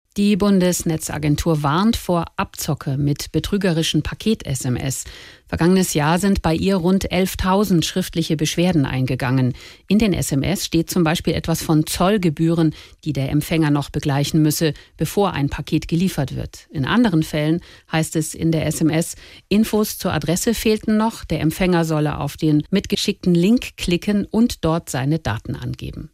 Kurzfassung der Warnung in den SWR3 Nachrichten: